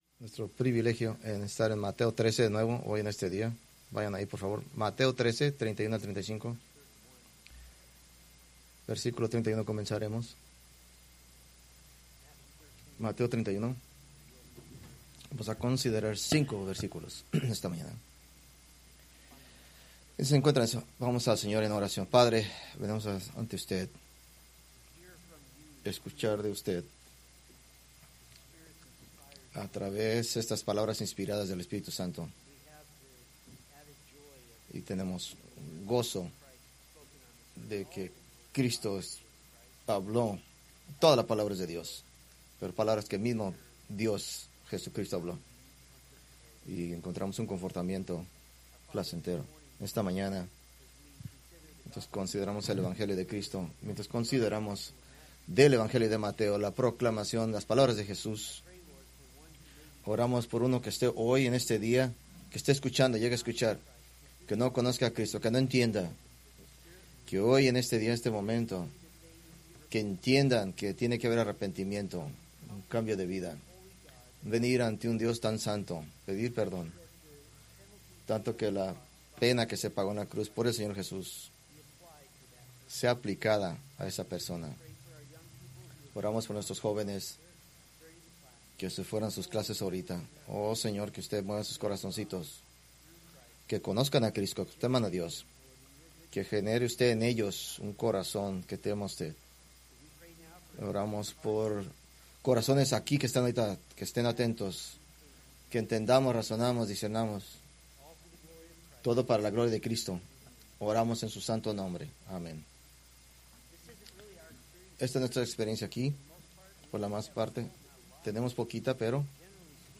Preached February 22, 2026 from Mateo 13:31-35